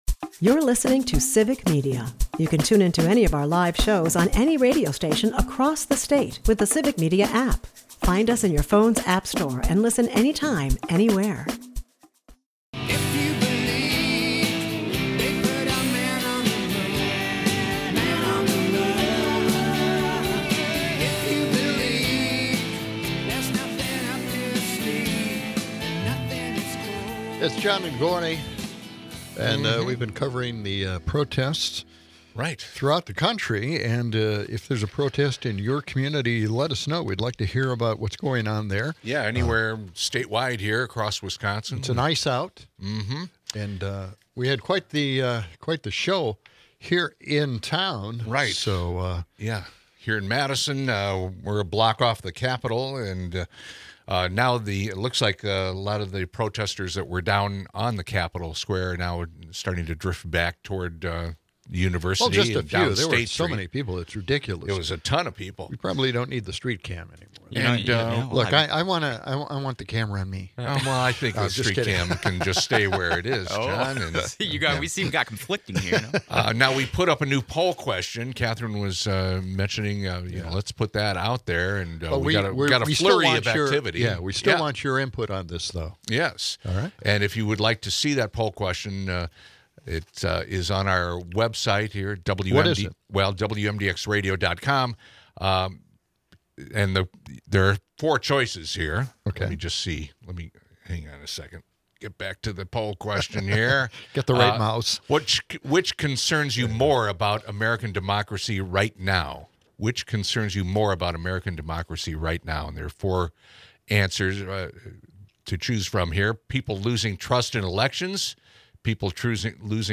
They debate a poll question about American democracy's biggest threat, with trust in elections leading concerns. Conversations veer into Trump's controversial policies, including his economic approach and impeachment talks. Listener calls add spice, discussing gun laws and political divides.